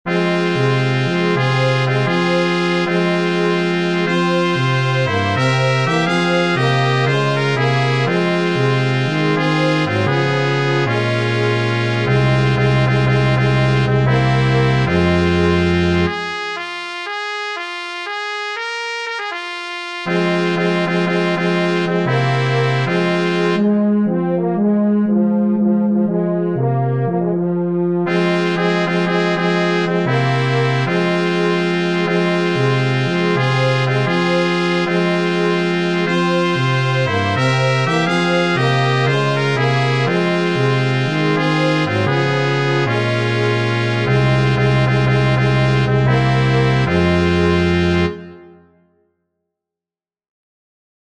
Download Arrangement for Band in E-flat (listen to audio below).
Wade_in_the_Water-Quartet.mp3